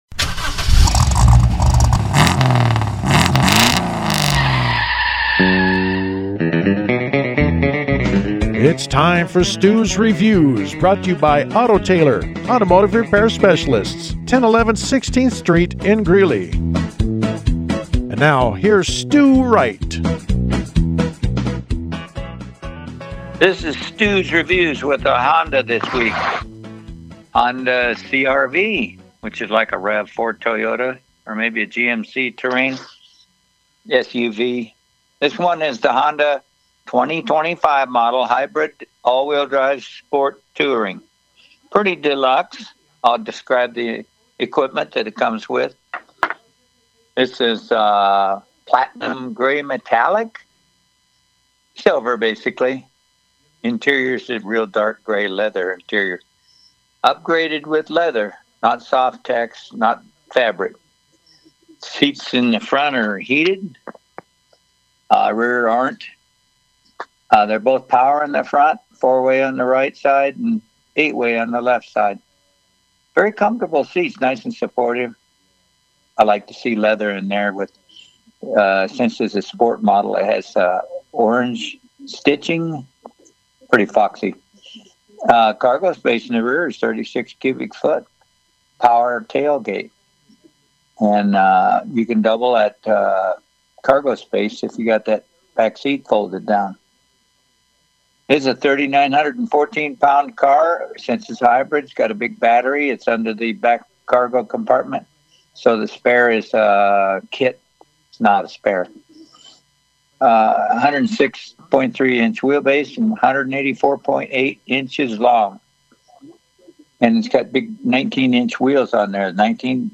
The Honda review was broadcast on Pirate Radio FM104.7 in Greeley: